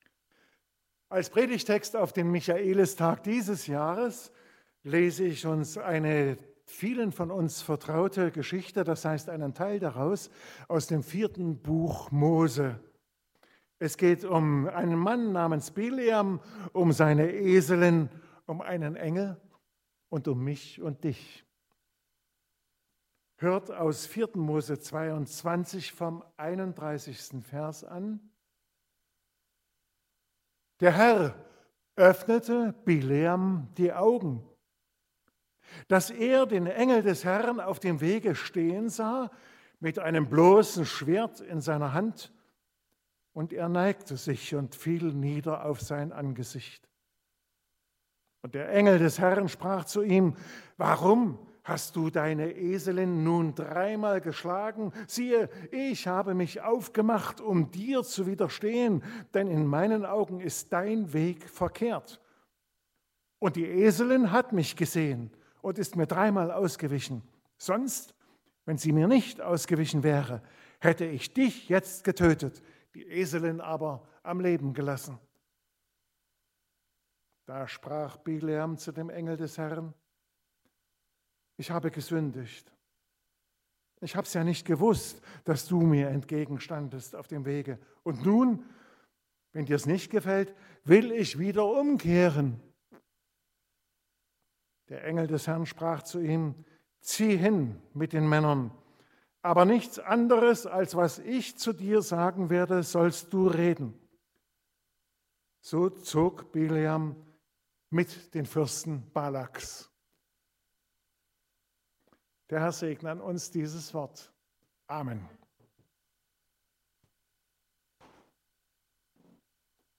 Mose 22, 31-35 Gottesdienstart: Predigtgottesdienst Obercrinitz Die Geschichte, um die es heute geht, versetzt uns zurück in die Zeit des Volkes Israel in der Wüste - 40 Jahre Wanderschaft durch fremde Länder.